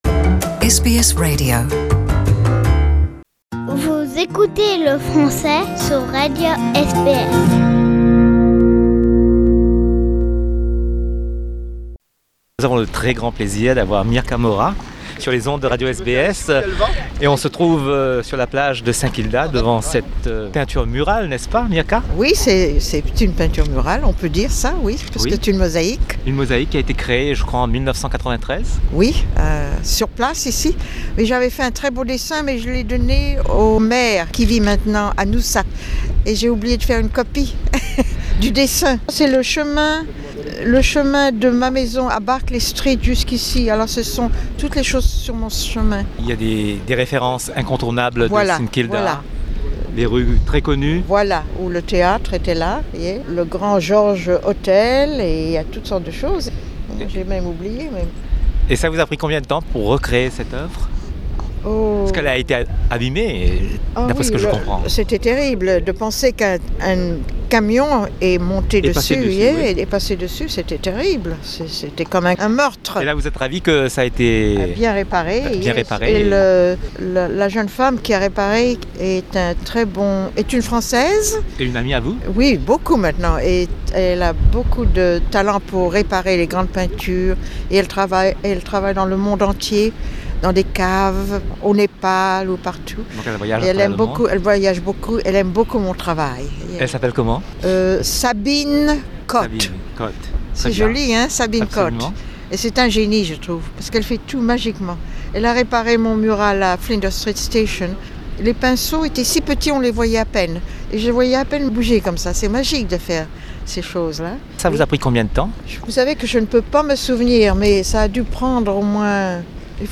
En hommage a Mirka Mora, nous ouvrons nos archives, c'était en 2010, nous l'avions rencontrée lors de la remise en état de sa mosaique ... sur la plage de Saint Kilda.